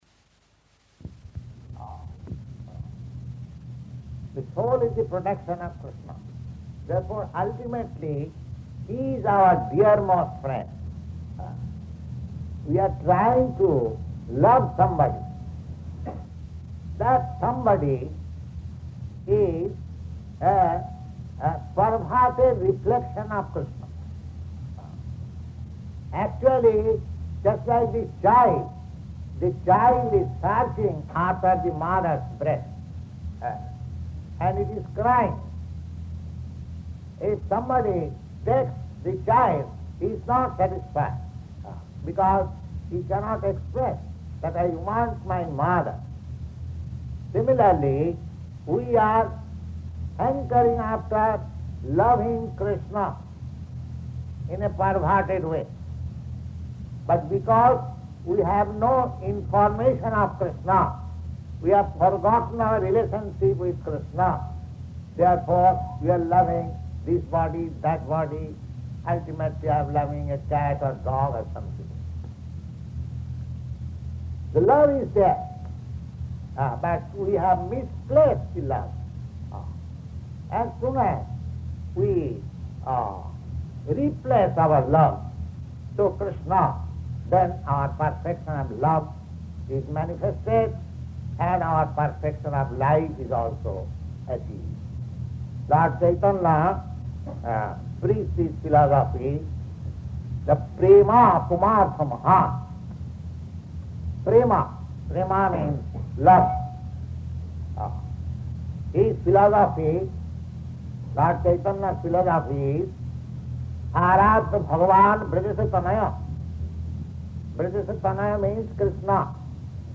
Lecture [partially recorded]
Location: San Francisco
[Poor audio]